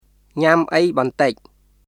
[ニャム・アイ・ボンタイ　ɲam ʔəi bɔntəc]